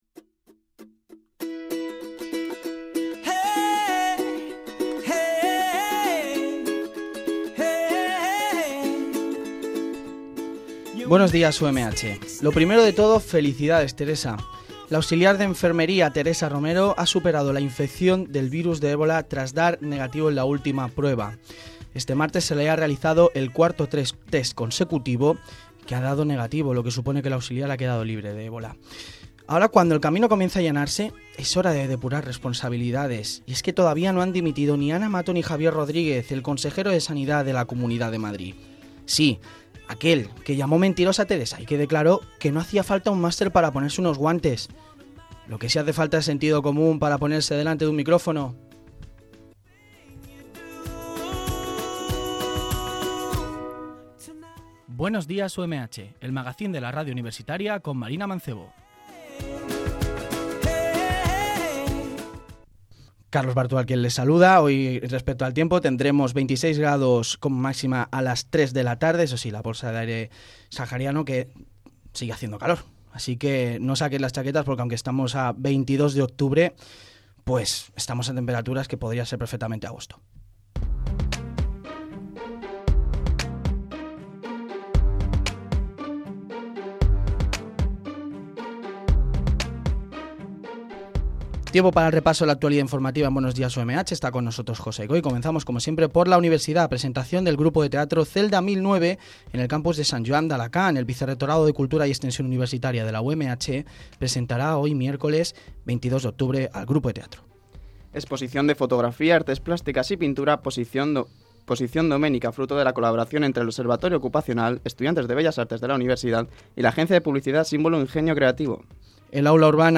Magacín diario “Buenos días UMH” que incluye noticias de la actualidad informativa y secciones elaboradas por estudiantes de Periodismo.